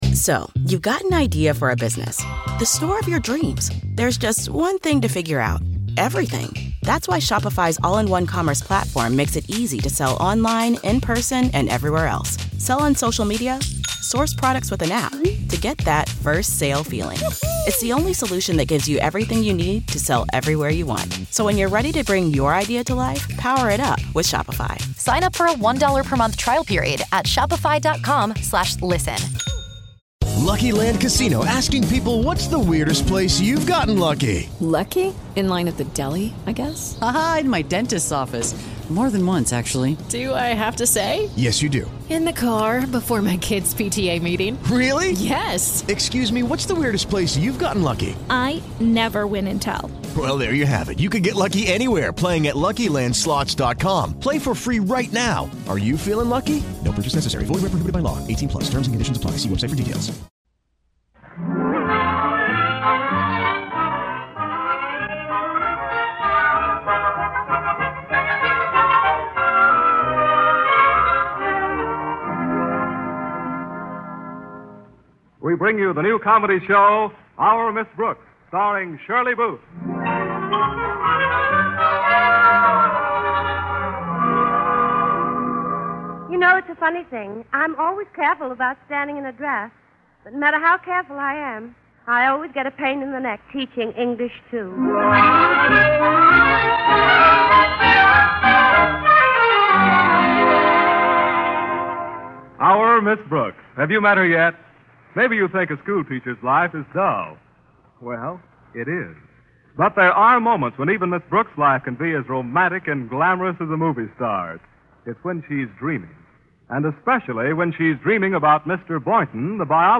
Our Miss Brooks was a beloved American sitcom that ran on CBS radio from 1948 to 1957.